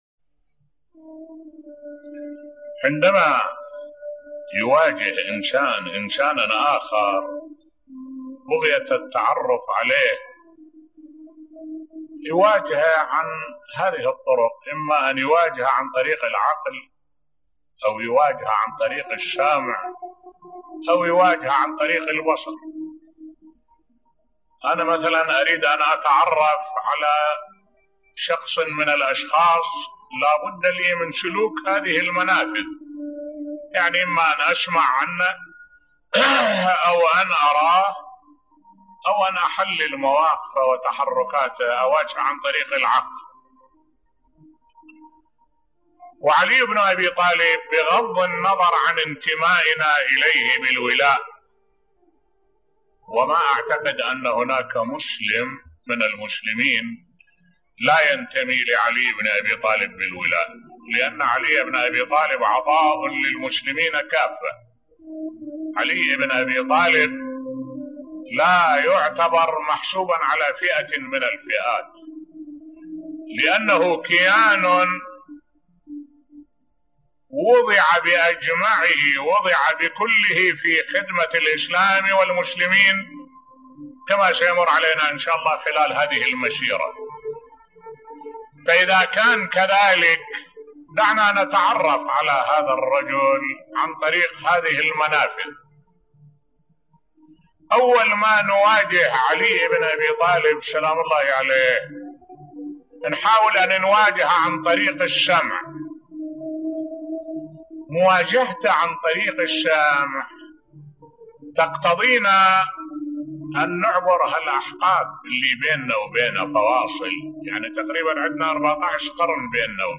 ملف صوتی كيف يتعرف الانسان على الطرف الاخر بصوت الشيخ الدكتور أحمد الوائلي